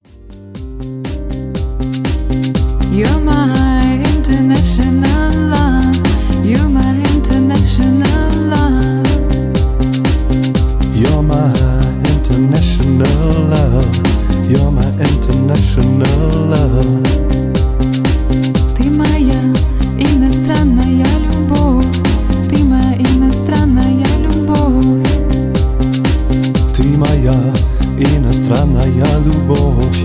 120 BPM Dance-Track